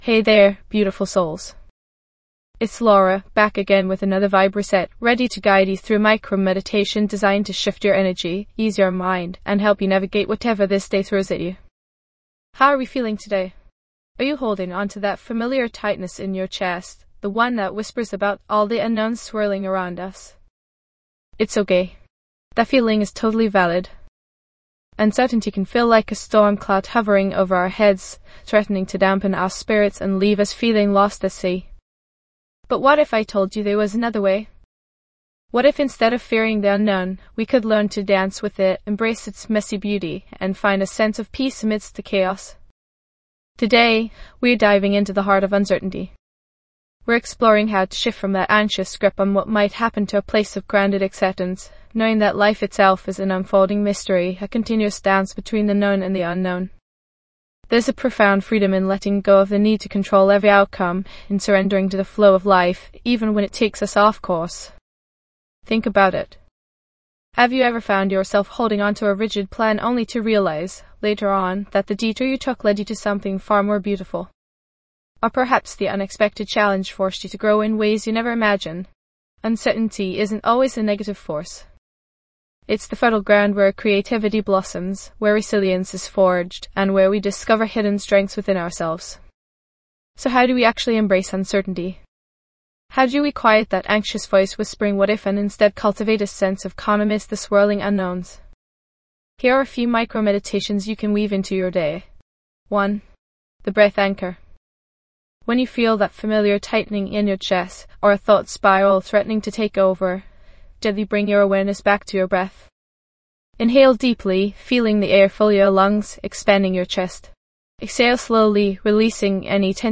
Episode Description:.Find peace amidst uncertainty with our guided meditation designed to help you embrace the unknown. Learn techniques to quiet your mind, reduce anxiety, and cultivate a sense of inner calm.